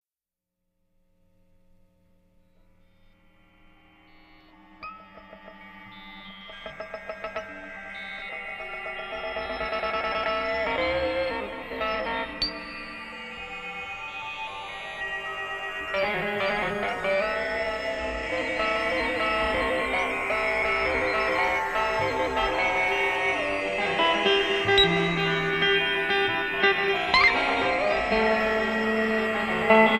Guitar
Synthesizer
3 great free improv master create CD!